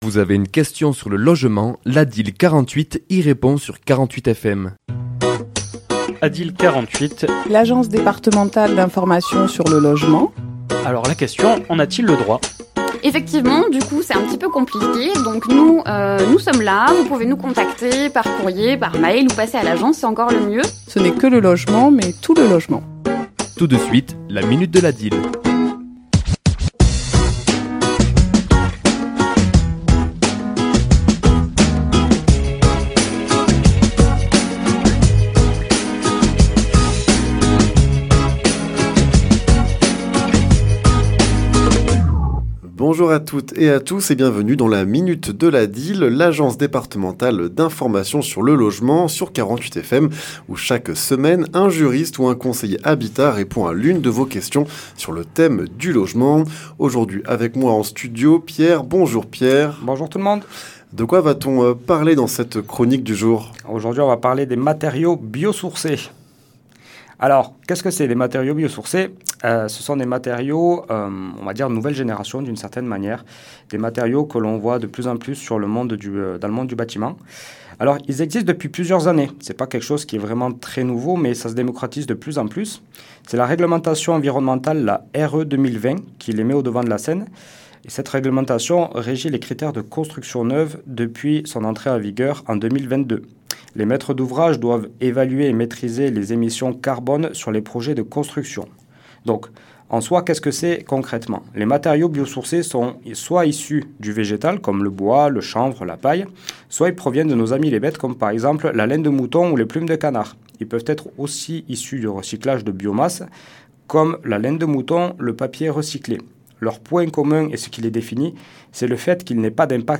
ChroniquesLa minute de l'ADIL
Chronique diffusée le mardi 23 novembre à 11h et 17h10